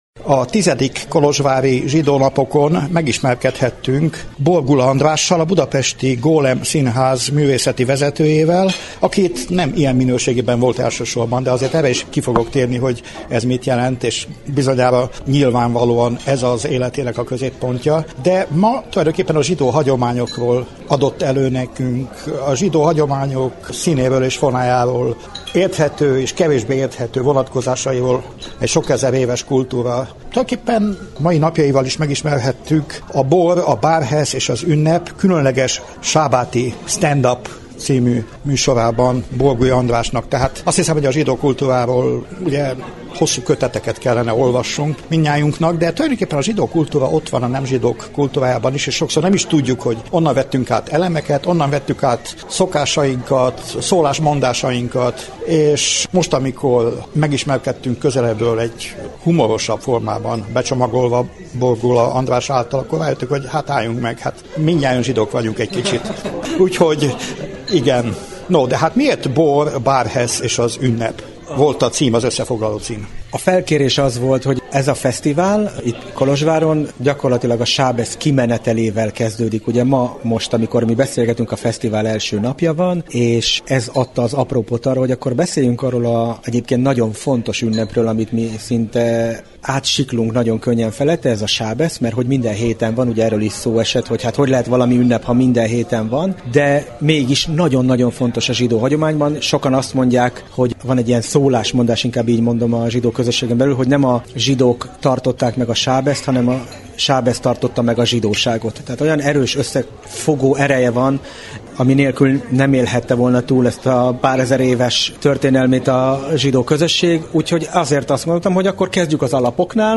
Összeállításunkban a tizedjére megszervezett fesztivál történéseit elevenítjük fel el az ott készült beszélgetések segítségével.
A nagy közönségsikert aratott eseményt követően készült a interjú Illényi Katicával, ez zárja összeállításunkat.